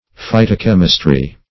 Phytochemistry \Phy"to*chem"is*try\